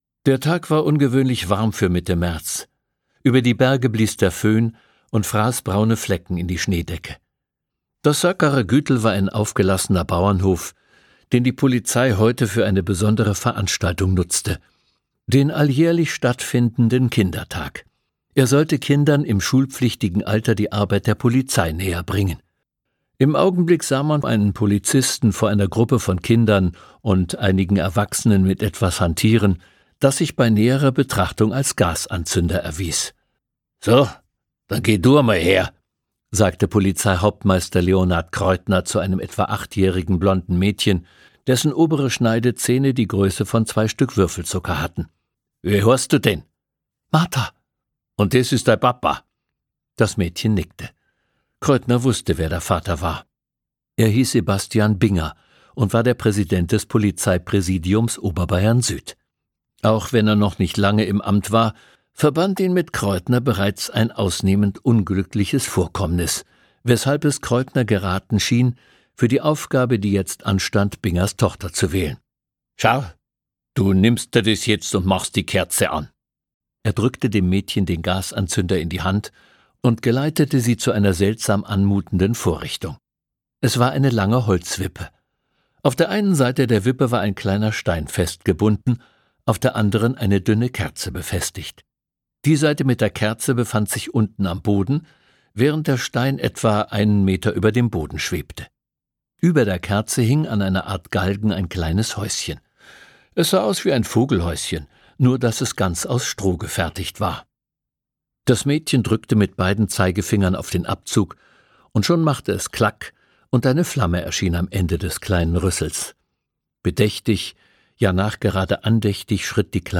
Bodenfrost - Andreas Föhr | argon hörbuch
Wallner und Kreuthner gewitzt und spannungsreich zu begleiten – auf unnachahmliche Weise bajuwarisch.